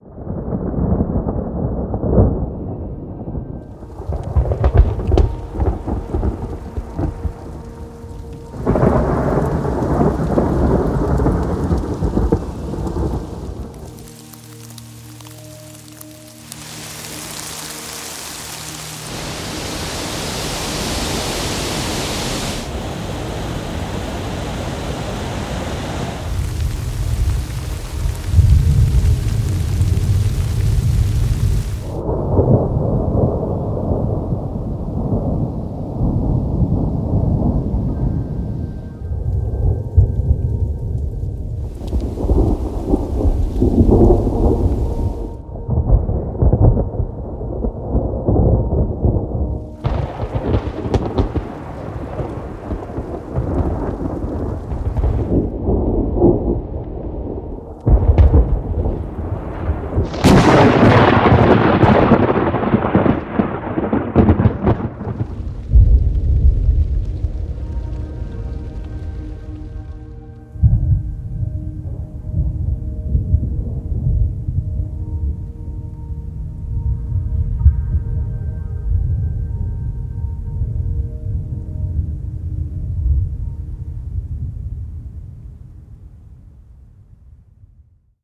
104种震撼的自然风暴天气环境无损环绕音效素材 Boom Library – Thunderstorm Chaser 3D Surround & Stereo
Thunderstorm Chaser 是由 Boom Library 制作的一款高品质自然风暴天气声音效库，专注于打雷和下雨的环境音效，提供 3D Surround 和 Stereo 两种版本。该库包含从远处低沉的雷声到近距离震撼的雷击声，此外还有雨声、冰雹声和风声，完美再现了完整的风暴环境。
声道数：立体声